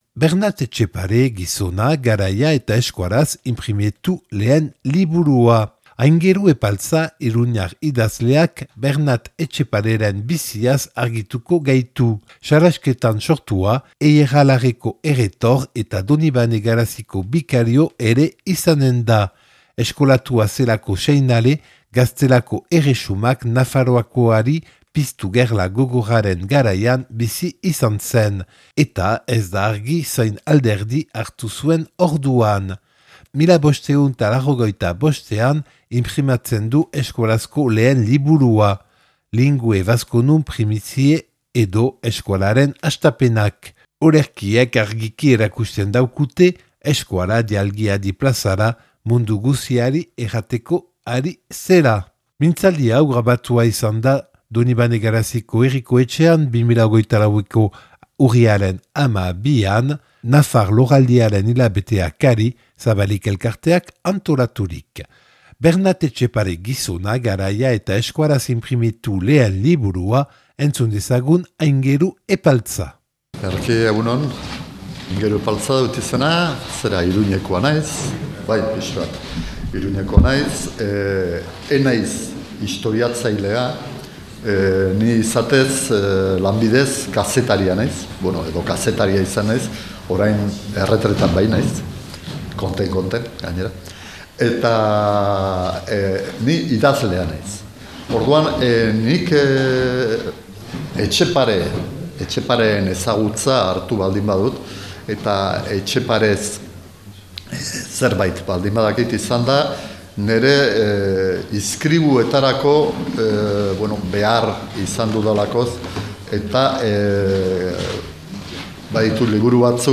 Donibane Garaziko Herriko Etxean grabatua 2024.